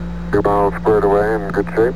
Radio voices soundbank 2
Voices Soundbank